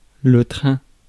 Ääntäminen
France: IPA: [lə tʁɛ̃]